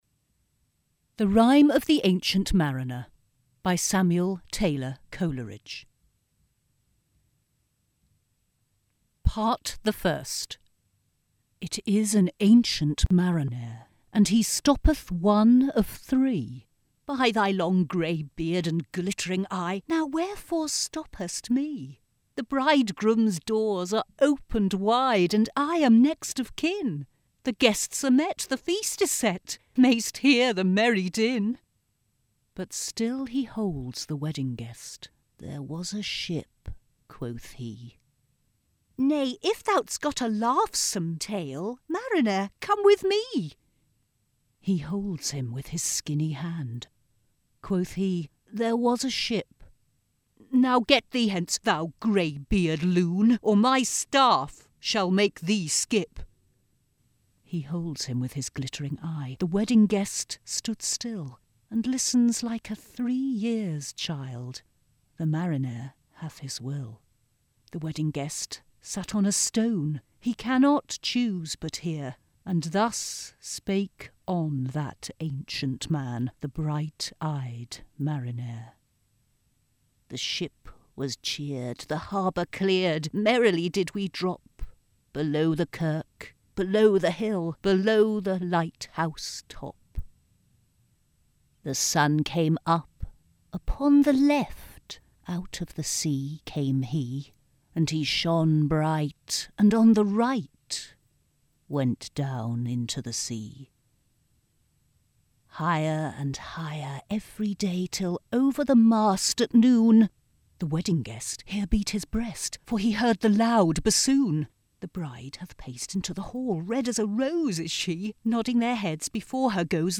Poetry Recordings for A Level English
ZigZag's new recordings really bring the texts to life, and as they are read by a number of professional artists (including speech artists and actors!), the variation ensures full engagement of students in the classroom!